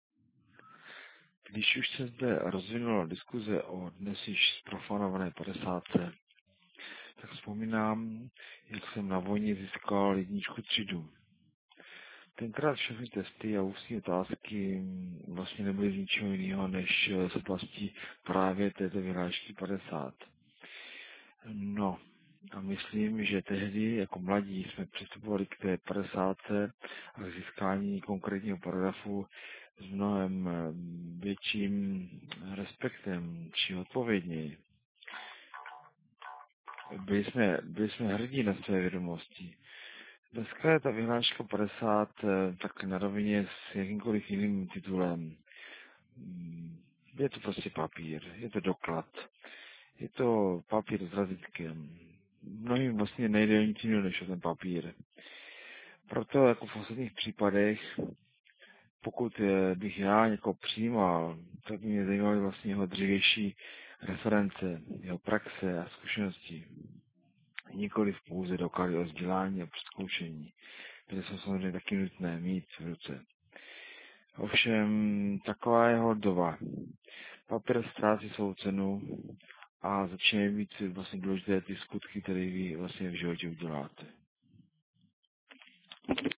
Hlasová reakce zde ...